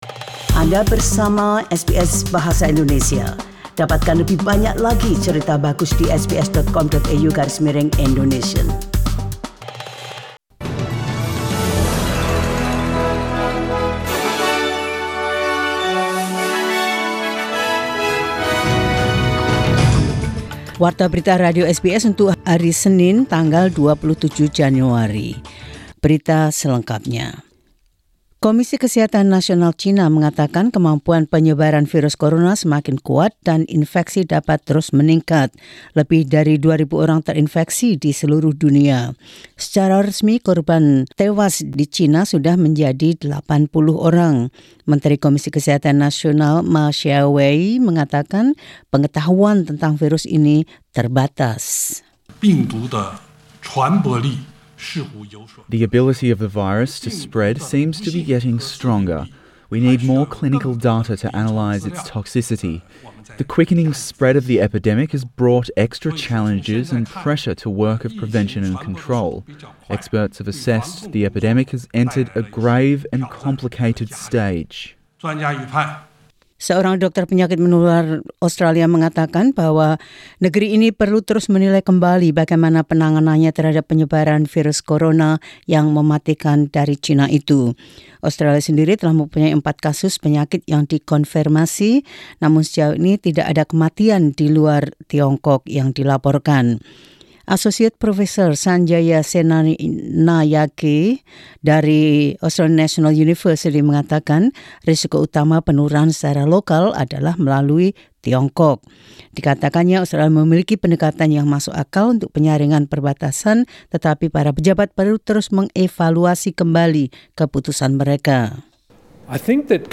Warta Berita Radio SBS dalam Bahasa Indonesia 27 Jan 2020.